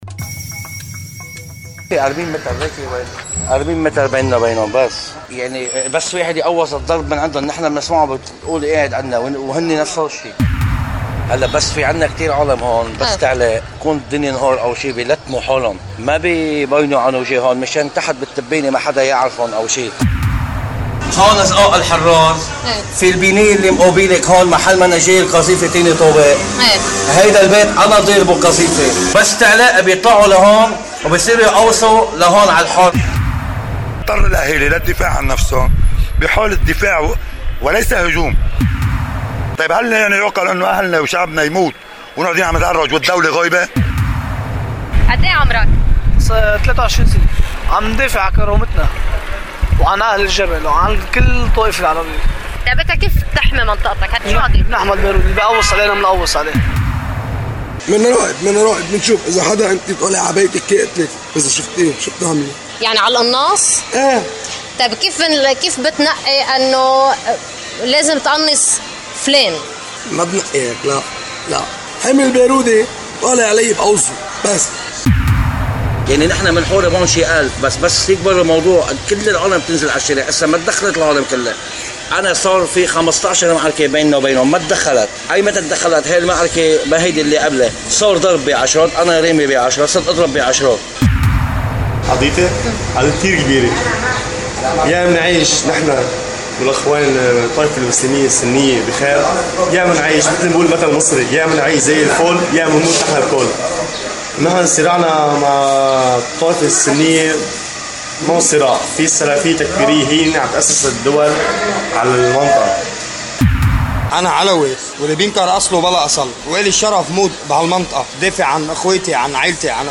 شهادات لمقاتلين من جبل محسن